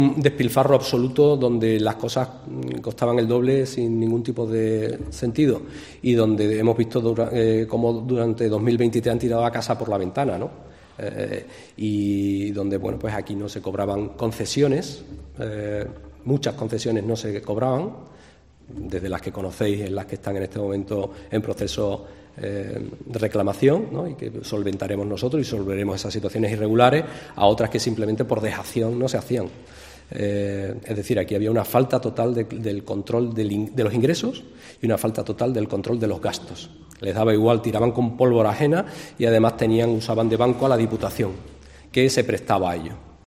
Felipe Arias, portavoz del equipo de Gobierno en el Ayuntamiento de Huelva